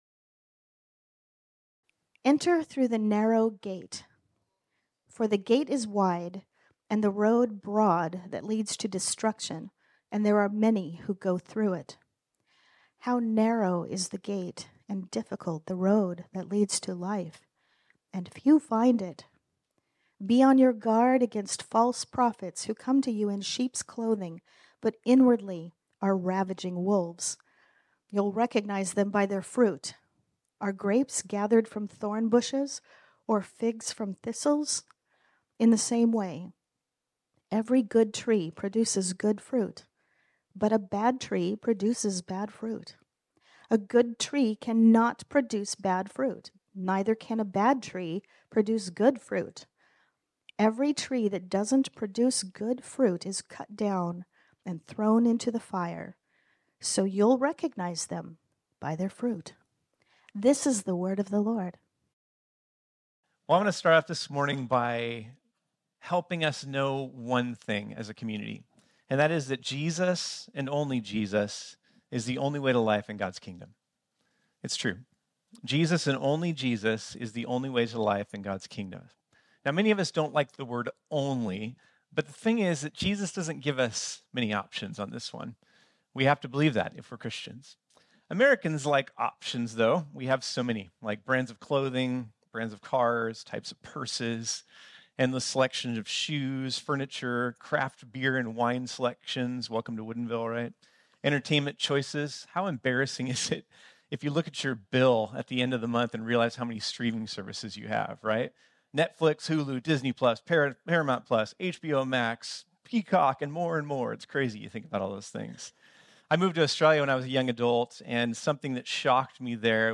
This sermon was originally preached on Sunday, May 19, 2019.